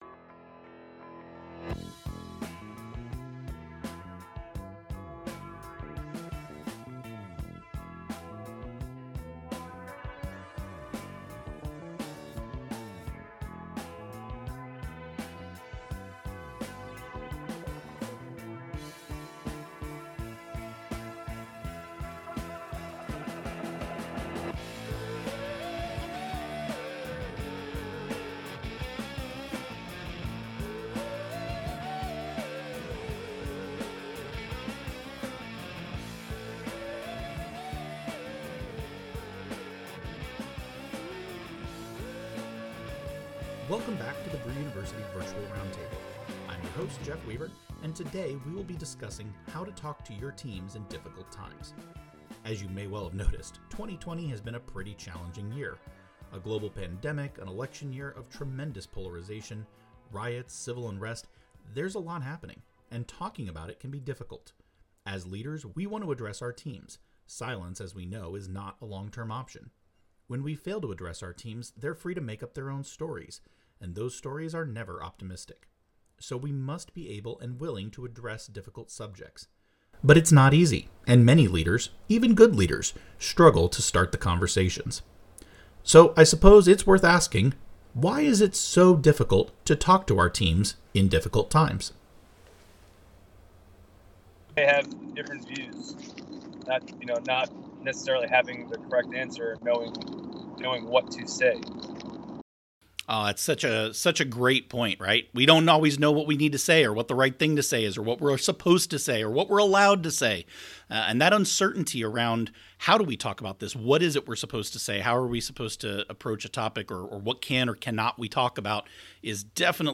Join us for a discussion about how to tackle difficult conversations with your teams on this episode of the Brew University™ Virtual Roundtable.